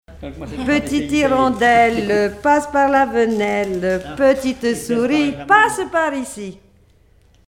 formulette enfantine : amusette
Chansons traditionnelles
Pièce musicale inédite